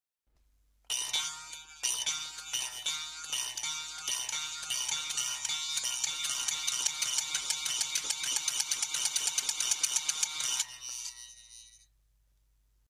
クルリンビン そのほか 2013年06月11日 竹の棒を持ち グルグルまわして音をだします ♪ ♪ ♪ ♪ ♪ ↓ 音がはいっています PR Responses0 Responses お名前 タイトル メールアドレス URL パスワード 竹のスリットドラム （大） ｜ Home ｜ 感覚ミュージアムで